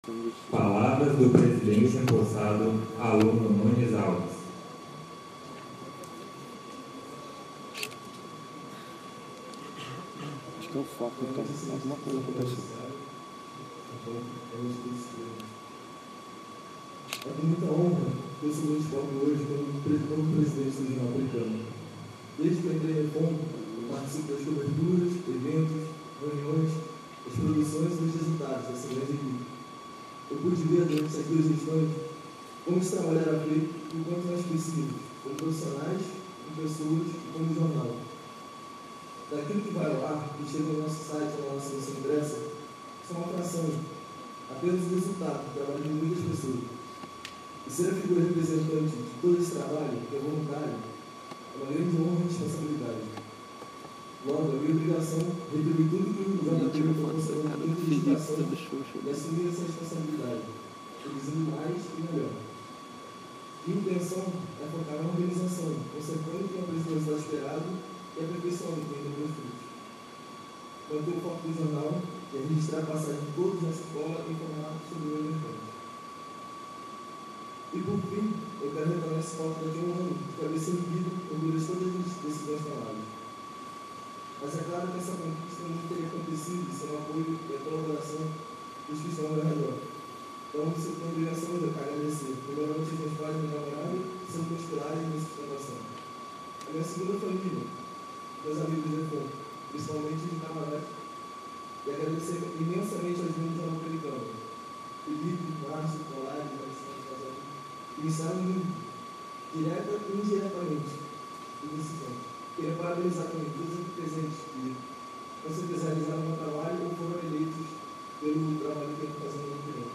No dia 7 de Dezembro, realizou-se no Auditório Almirante Newton Braga, a passagem de comando dos principais grêmios da Escola de Formação de Oficiais da Marinha Mercante (EFOMM).
Passagem de Comando do Jornal Pelicano